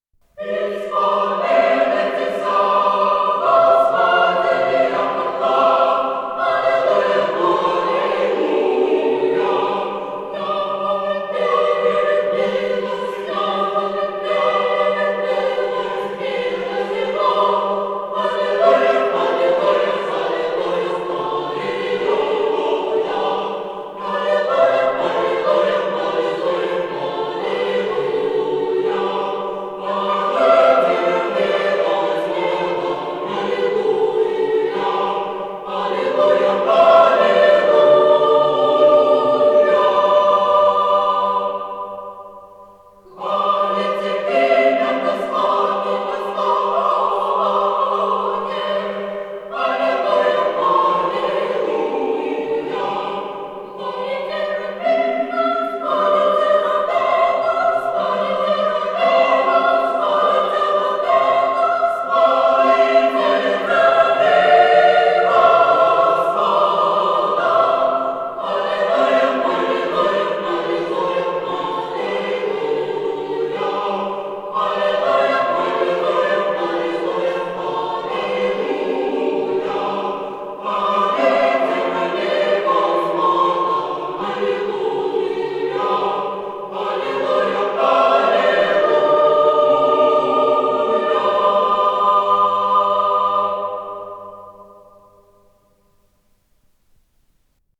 ИсполнителиКамерный хор
Скорость ленты38 см/с
ВариантДубль моно